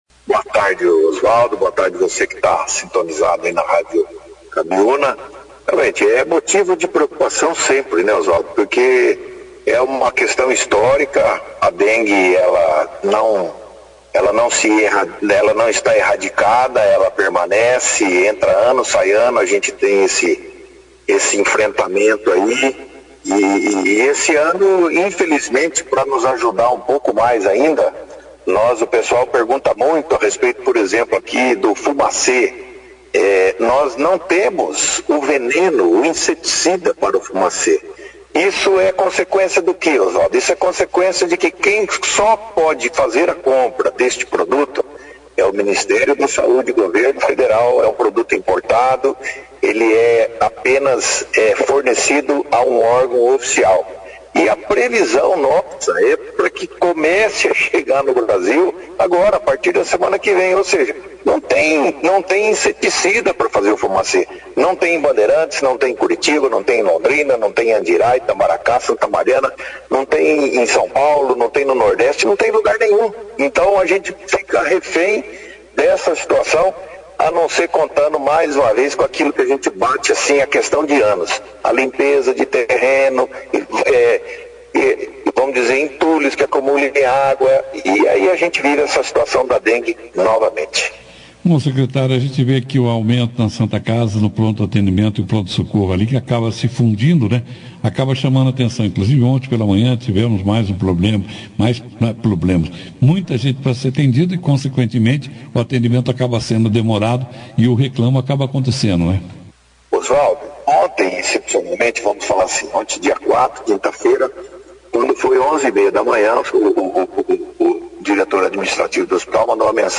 O Secretário de Saúde de Bandeirantes, Wanderson de Oliveira (foto), participou da 2ª edição do jornal Operação Cidade desta sexta-feira, 05/05, para falar sobre o que pode ser feito para solucionar o problema que a comunidade vem enfrentando devido aos inúmeros casos de Dengue.